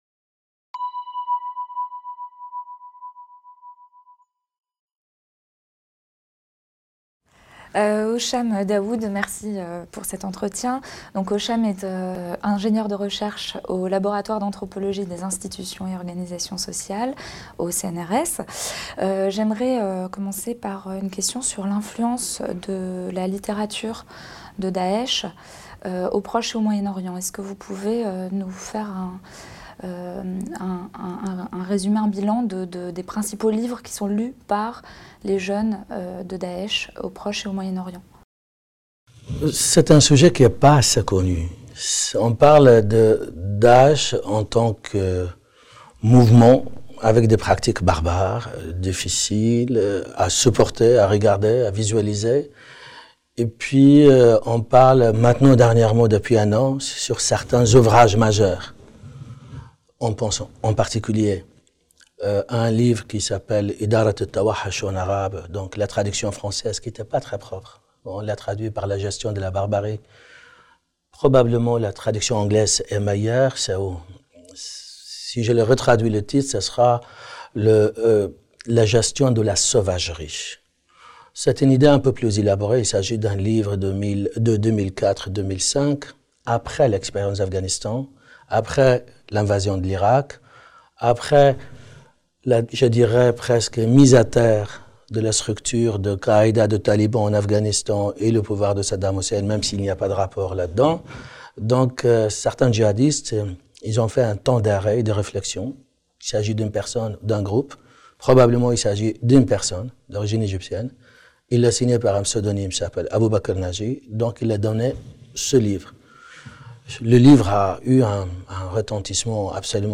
Entretien
Traduit de l'espagnol en français